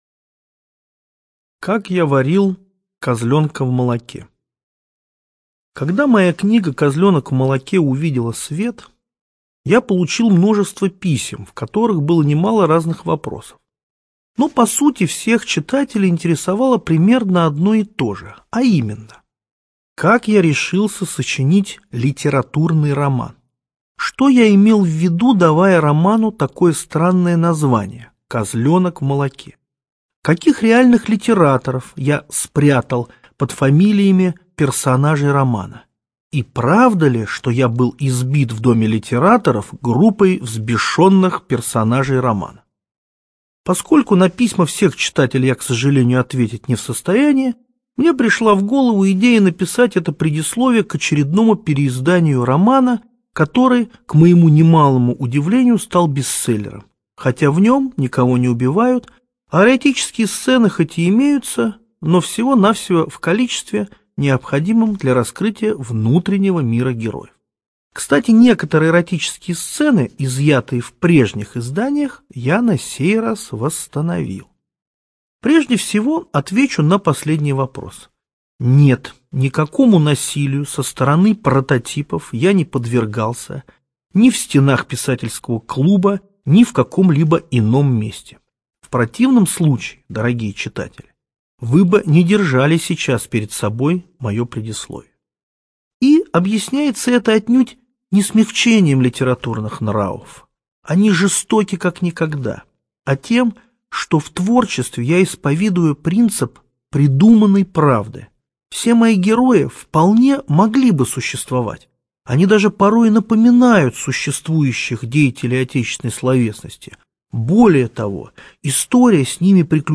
Студия звукозаписиБиблиофоника